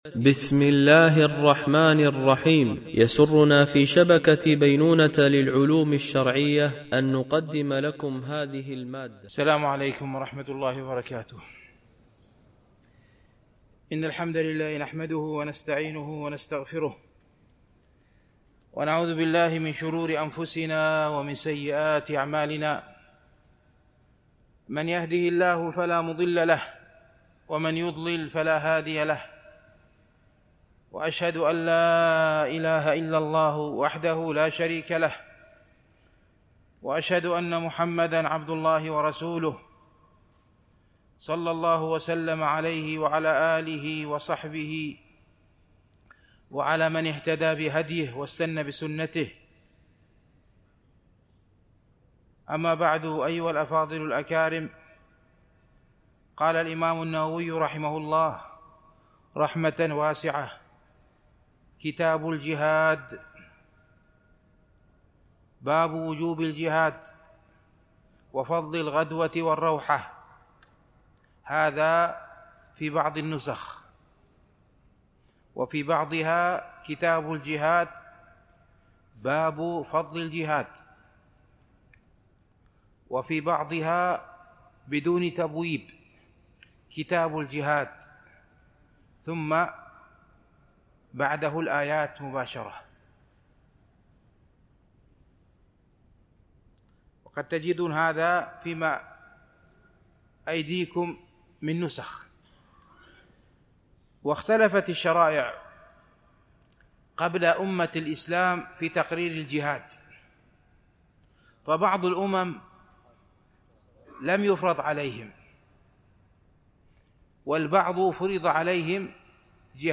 شرح رياض الصالحين – الدرس 345 ( كتاب الجهاد )